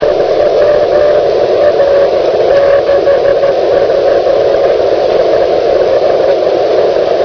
〜モービルのRIGでEMEのシグナルを聞いてみました。〜
アンテナはいつもの１４エレのスタックです。周波数は144MHzです。
しかし、またいくつかの信号を録音をしてきました。
（手元の資料では４ヤギとなっていて、今回聞いた中では最もアンテナの小さい局。この部分だけではコピーできないが、何度も聞いてコールサインをコピーした。）